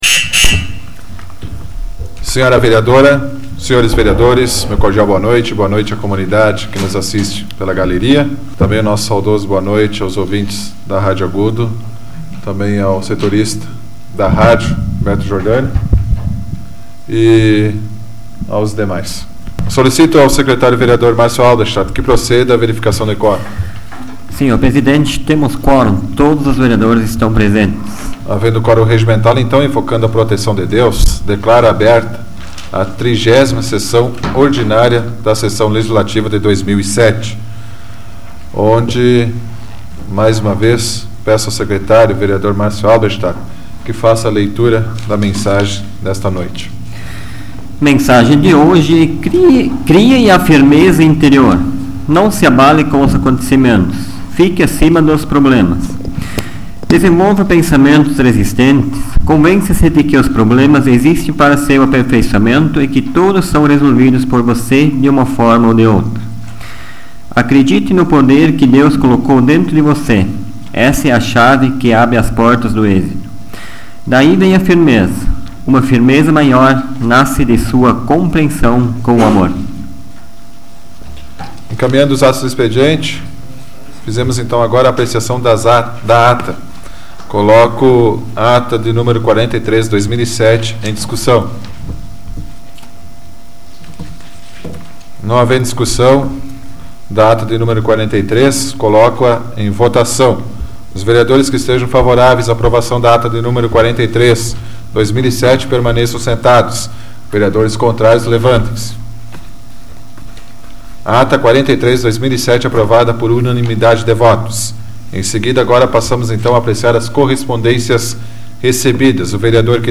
Áudio da 104ª Sessão Plenária Ordinária da 12ª Legislatura, de 29 de outubro de 2007